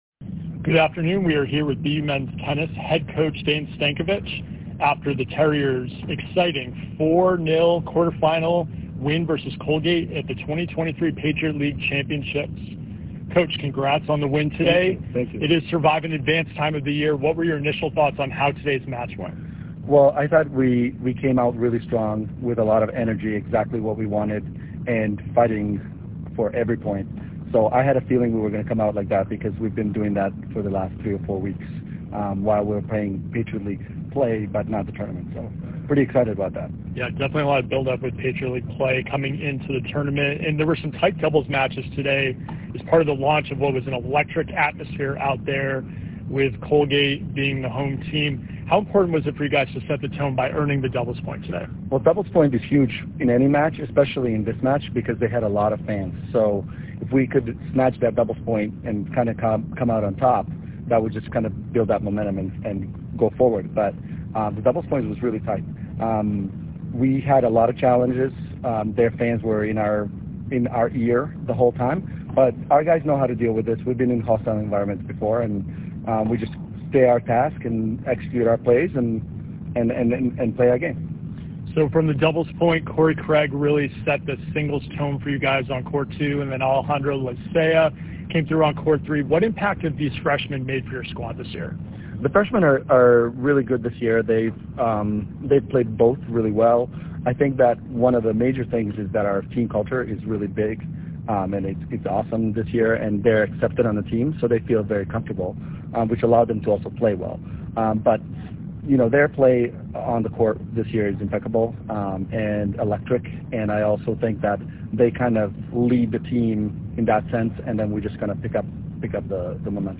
Colgate PL Quarterfinals Postmatch Interview